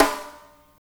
Tuned drums (E key) Free sound effects and audio clips
• Big Room Acoustic Snare Sample E Key 22.wav
Royality free snare sample tuned to the E note. Loudest frequency: 2009Hz
big-room-acoustic-snare-sample-e-key-22-ZJB.wav